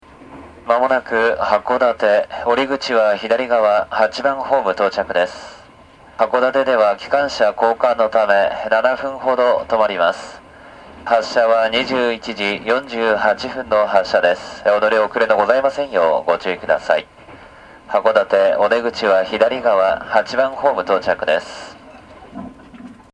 函館到着のアナウンス（再生時間23秒）
030-hakodatetouchaku.mp3